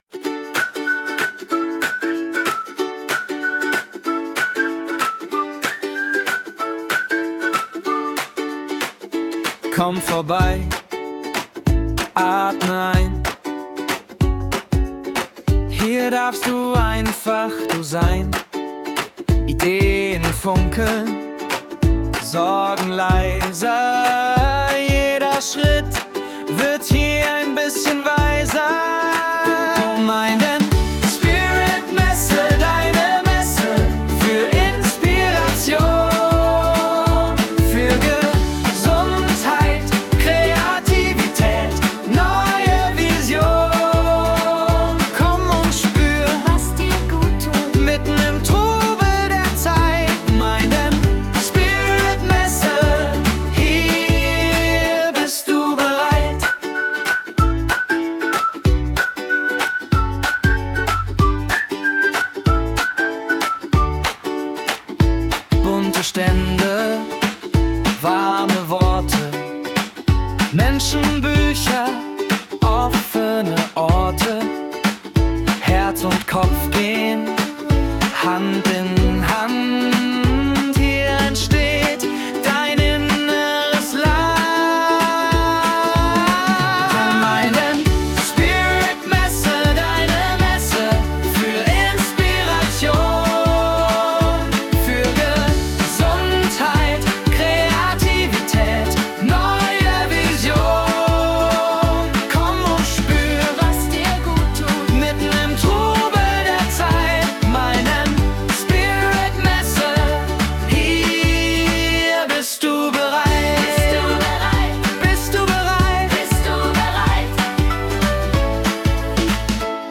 Lüpfig, frisch, ansteckend und gibt gute Laune.